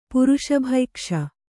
♪ puruṣa bhaikṣa